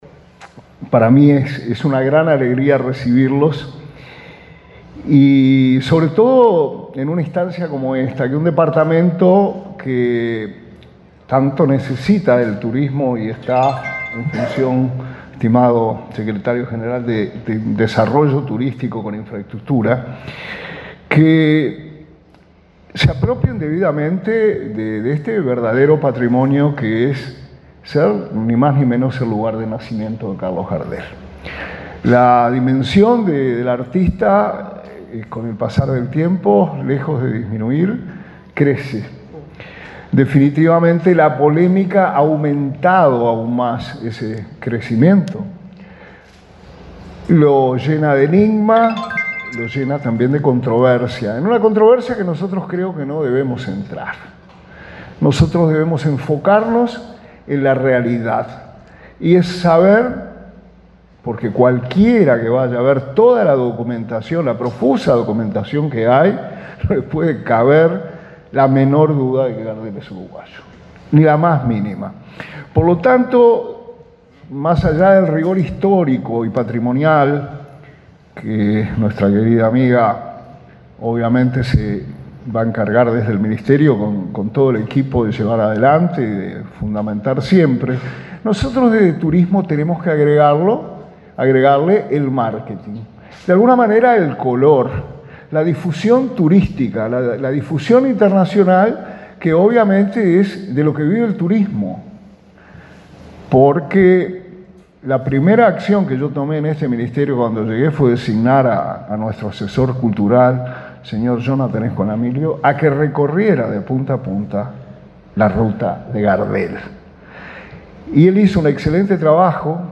Palabras del subsecretario de Turismo, Remo Monzeglio
Este miércoles 2 en Montevideo, el subsecretario de Turismo, Remo Monzeglio, participó del lanzamiento del Festival Internacional de Tango Carlos